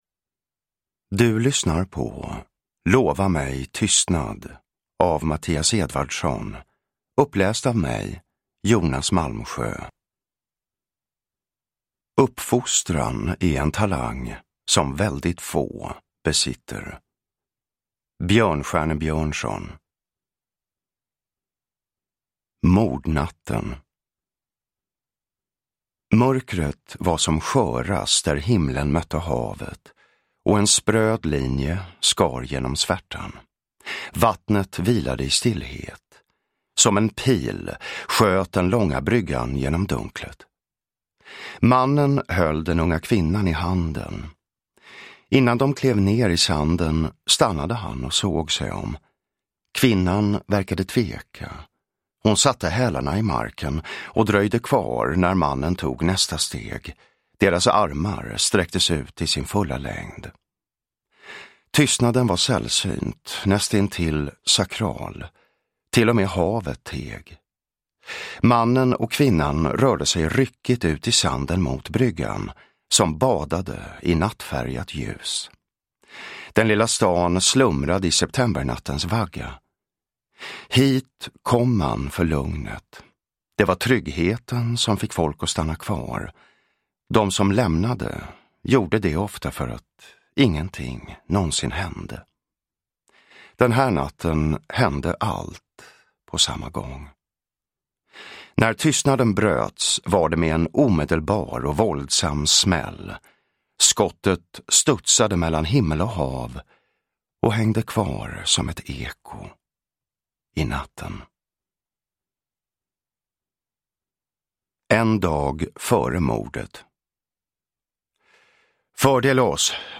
Lova mig tystnad – Ljudbok – Laddas ner
Uppläsare: Jonas Malmsjö